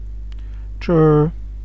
tschö, letsche, Matsch chat
Ksh-M-Tschöö.ogg.mp3